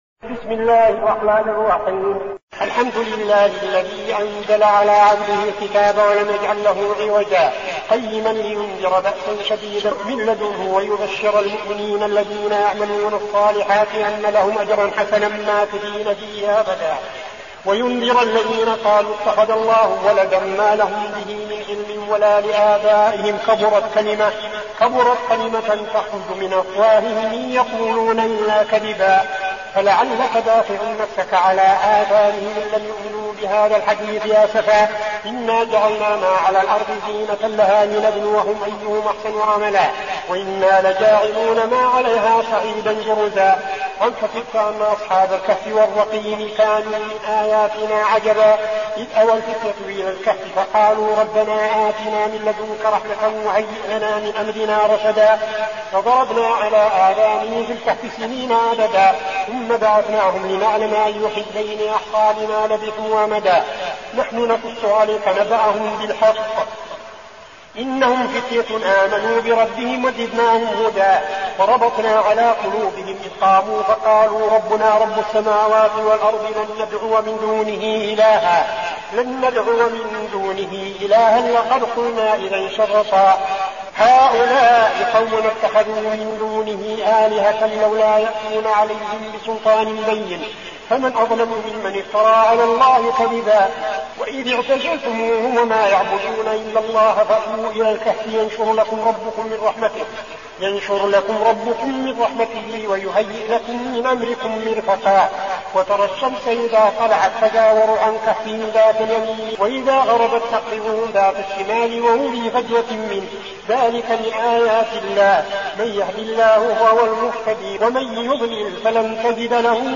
المكان: المسجد النبوي الشيخ: فضيلة الشيخ عبدالعزيز بن صالح فضيلة الشيخ عبدالعزيز بن صالح الكهف